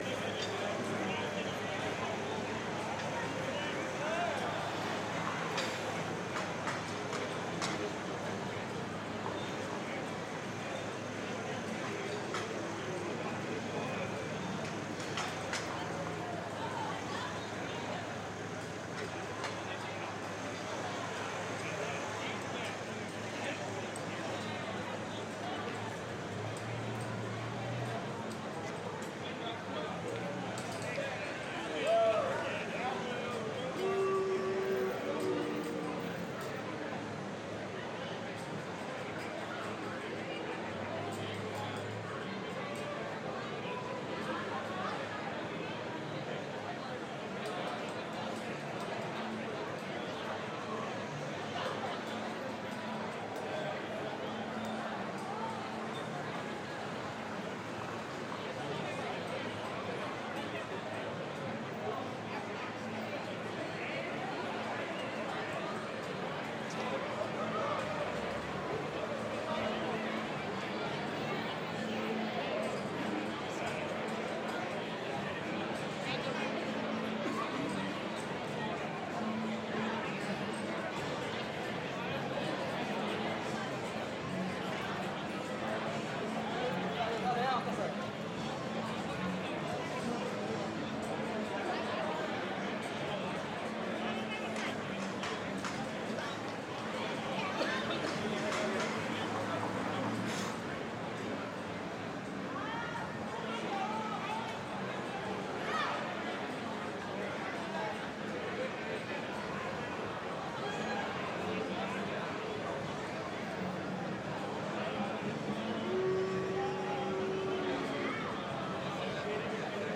Busy City Street.mp3